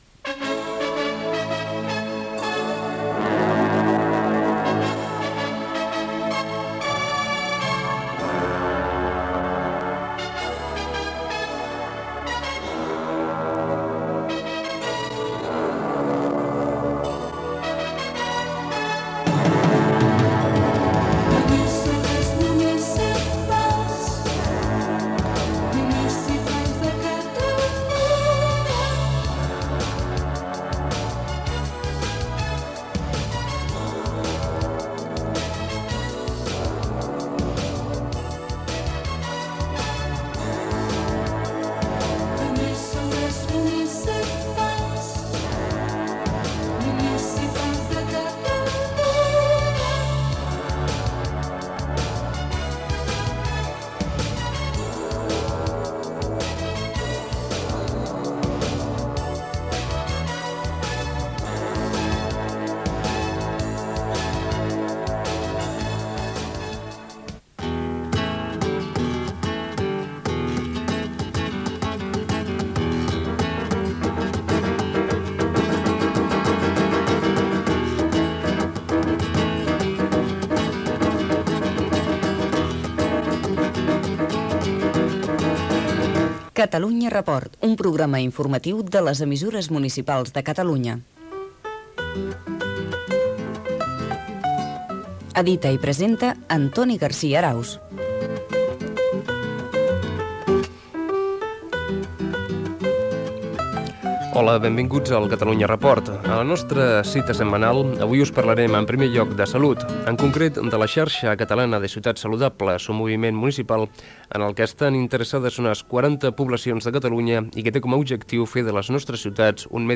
Sintonia de les Emisssores Municipals de Catalunya, careta del programa, sumari, xarxa catalana de ciutats saludables, entrevista a l'alcalde de Granollers Josep Pujadas sobre els Jocs Olímpics de Barcelona, les cases regionals catalanes, "Ha estat notícia", comiat i sintonia de l'EMUC Gènere radiofònic Informatiu